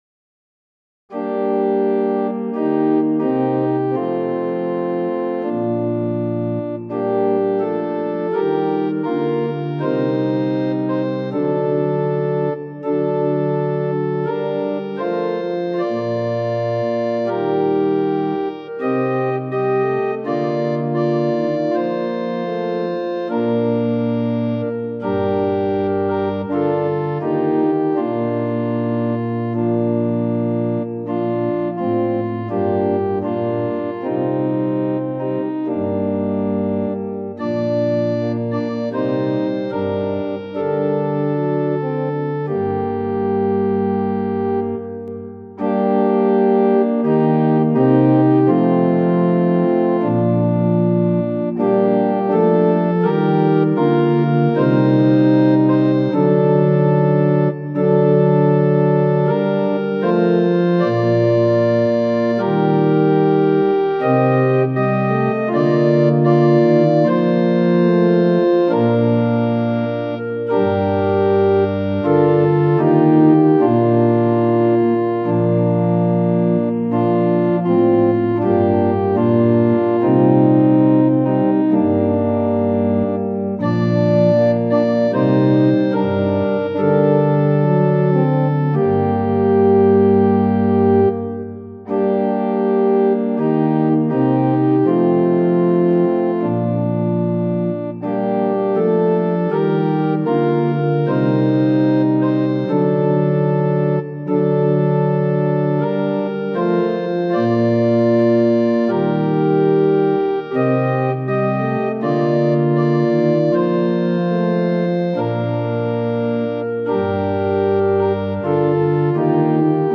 ♪賛美用オルガン伴奏音源：
・節により音色または音量が変わります
・間奏は含まれていません
Tonality = g
Pitch = 440
Temperament = Equal